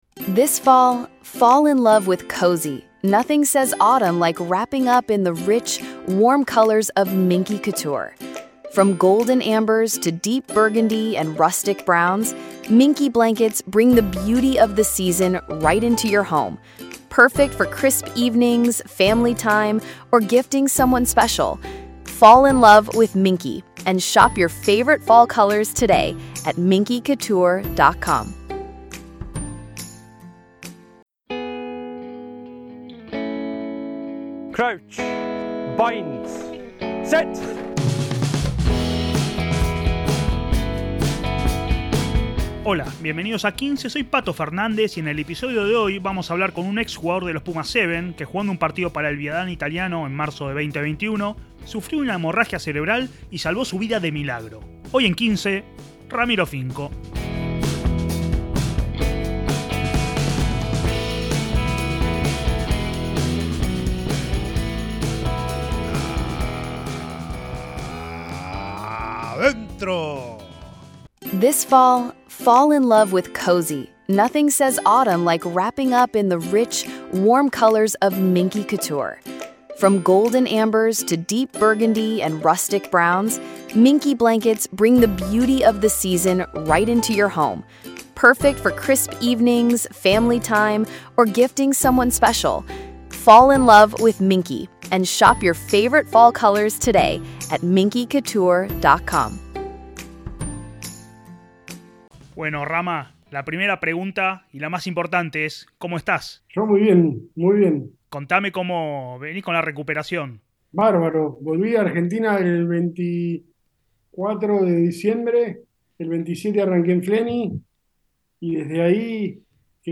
¡Charlas de rugby con los protasgonistas!